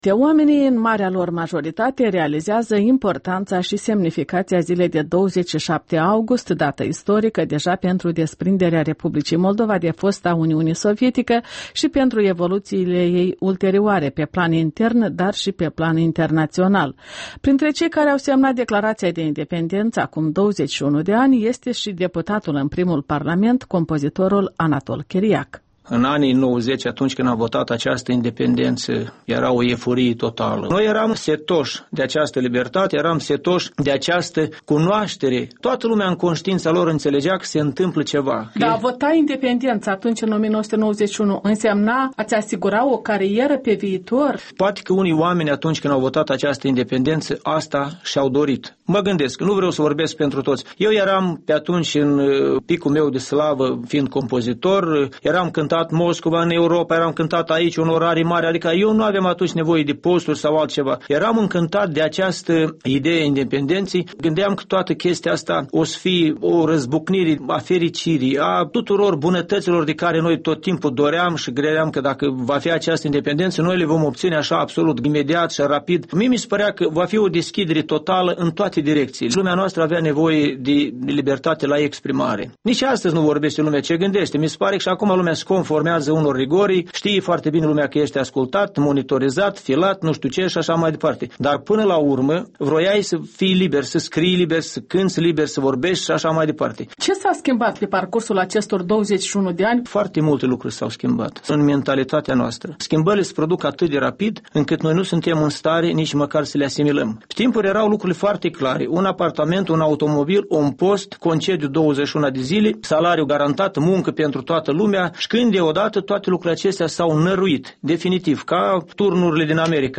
Compozitorul Anatol Chiriac și un interviu prilejuit de Ziua Independenței Moldovei